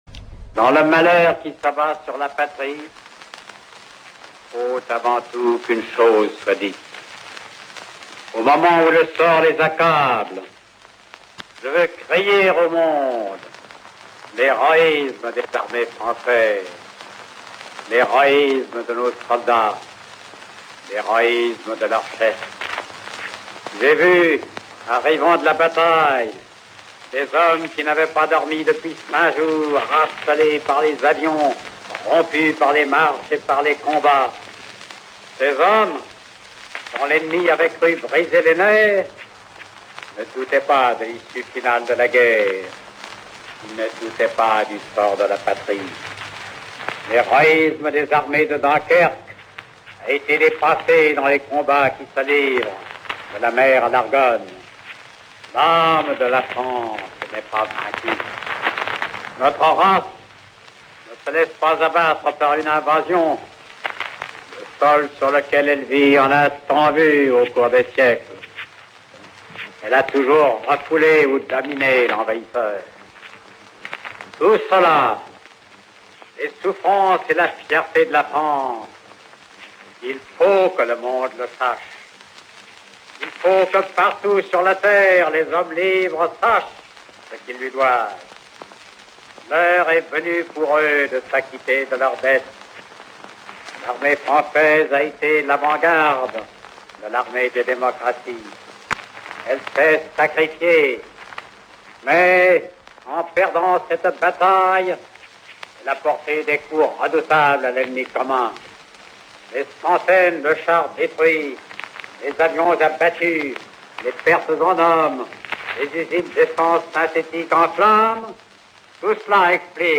13 juin 1940. — Dernière allocution de Paul Reynaud
L’allocution est prononcée à 23 h 30, après la fin du Conseil des ministres.